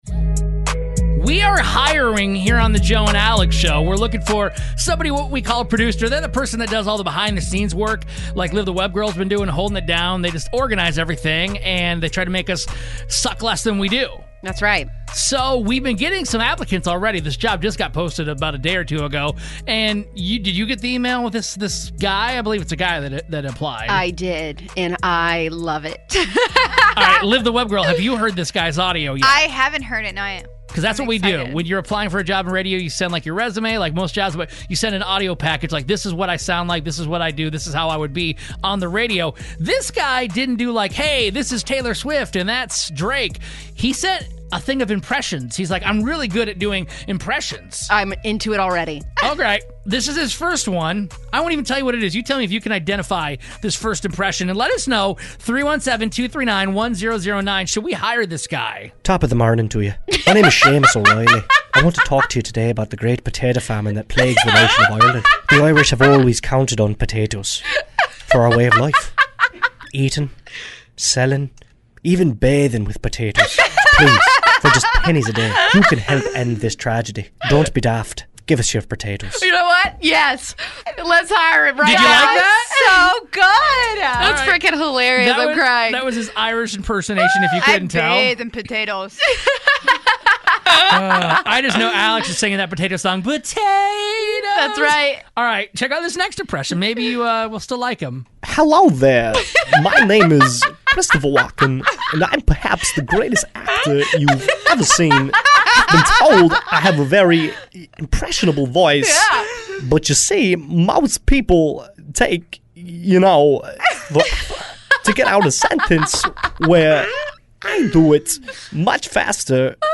A New Guy Applying For The Show Does Impressions
We're looking for a new Executive Producer and one of the applicants does impressions so we listened to them live on air and were DYING.